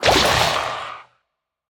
Minecraft Version Minecraft Version 1.21.5 Latest Release | Latest Snapshot 1.21.5 / assets / minecraft / sounds / mob / drowned / convert3.ogg Compare With Compare With Latest Release | Latest Snapshot